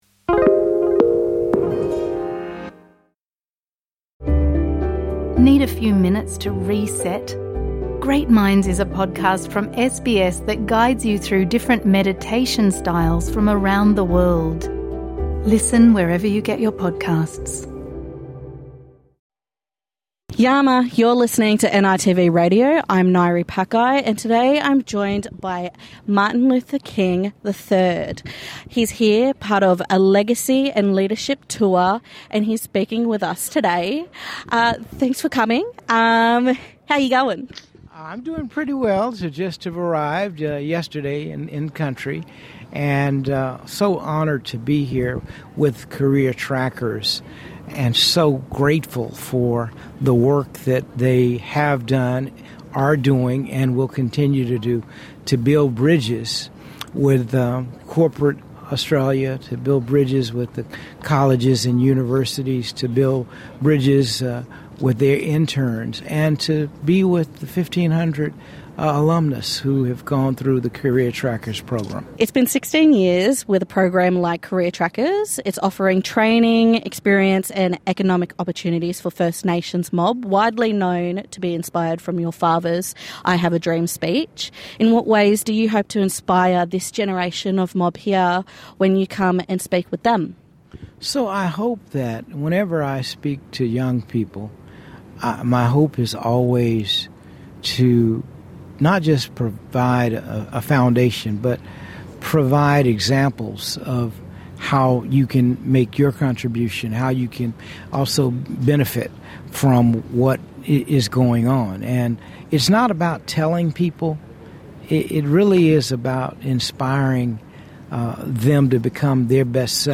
NITV Radio speaks with Martin Luther King III after morning performances and speeches to talk about First Nations economic empowerment, self-determination and pathways to progress.